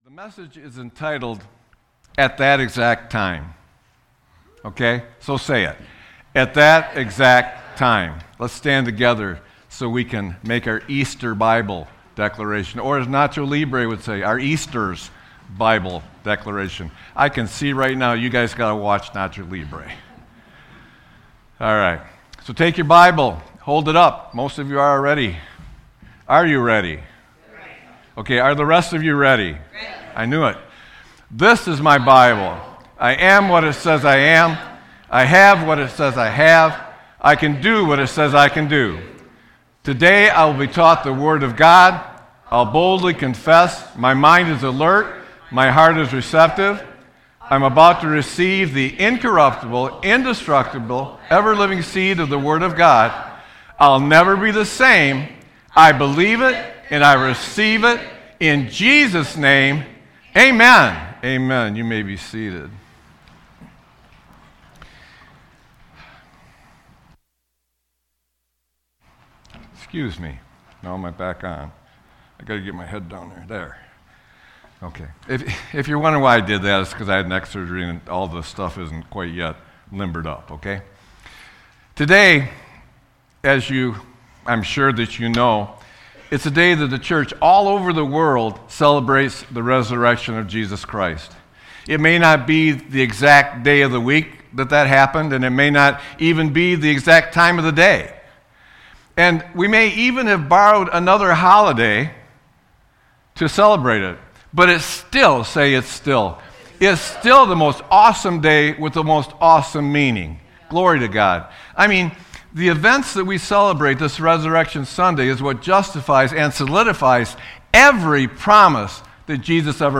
Sermon-4-05-26.mp3